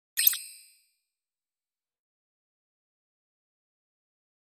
جلوه های صوتی
دانلود صدای کلیک 4 از ساعد نیوز با لینک مستقیم و کیفیت بالا
برچسب: دانلود آهنگ های افکت صوتی اشیاء دانلود آلبوم صدای کلیک از افکت صوتی اشیاء